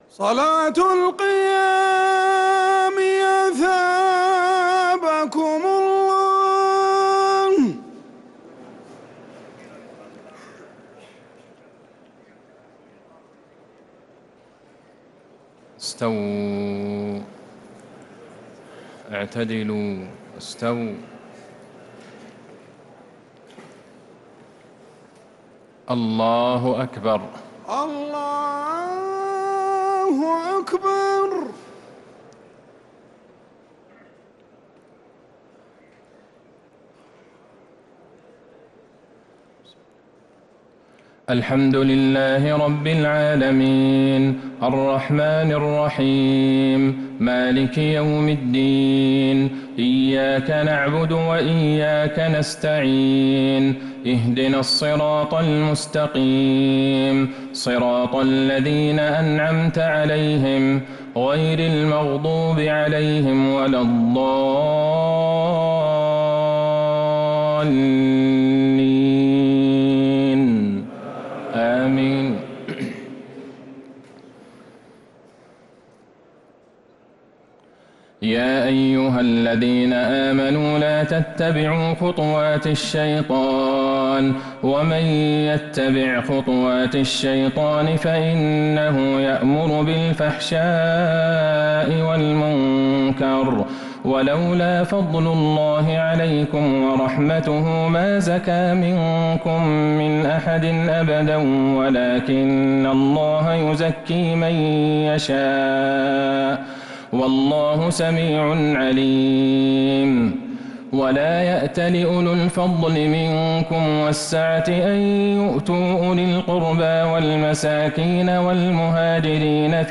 تهجد ليلة 22 رمضان 1447هـ من سورة النور (21-64) |Thajjud 22nd night Ramadan 1447H Surah Al-Nur > تراويح الحرم النبوي عام 1447 🕌 > التراويح - تلاوات الحرمين